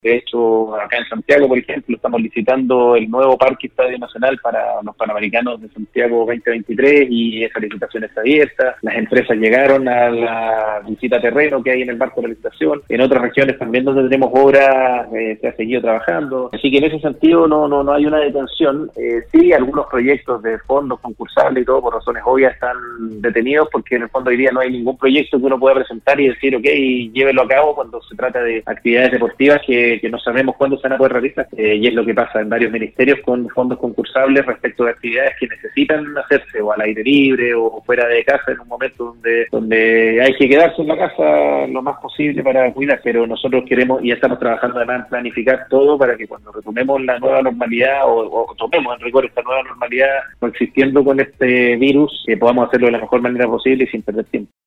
La mañana de este viernes, Andrés Otero, subsecretario del Deporte, sostuvo un contacto telefónico en el programa Al Día de Nostálgica, destacó la importancia del deporte como un beneficio para la salud, el espíritu, y en definitiva, para el bienestar de las personas.